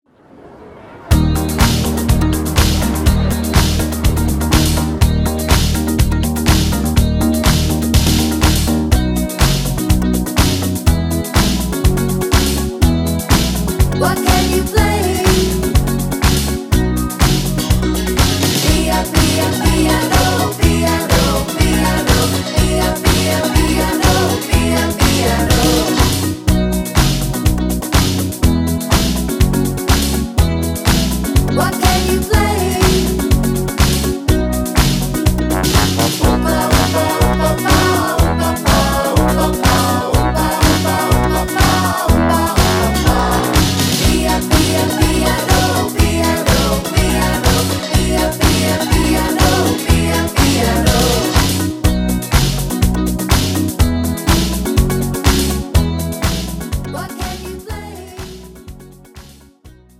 음정 (-1키)
장르 pop 구분 Pro MR